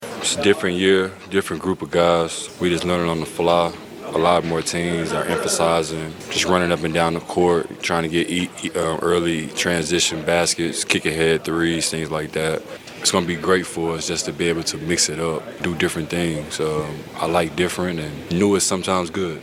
Bucks forward Bobby Portis said that the team has a new look.